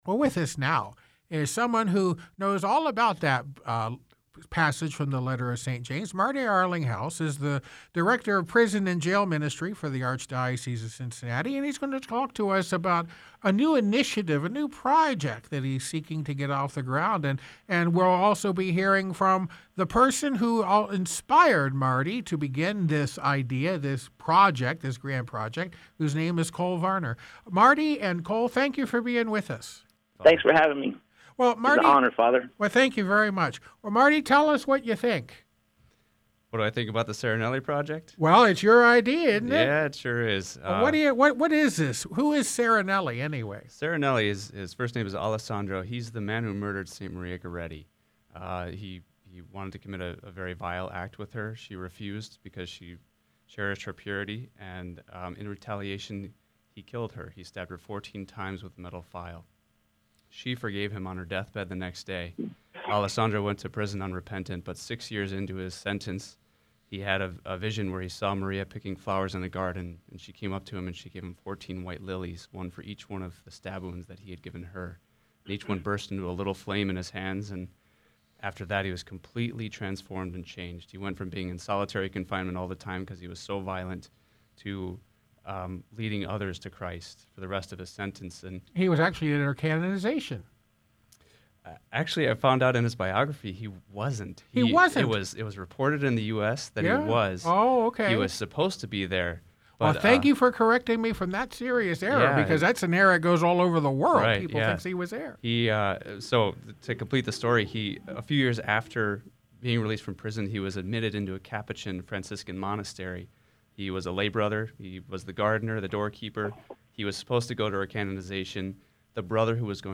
on Sacred Heart Radio about the Serenelli Project.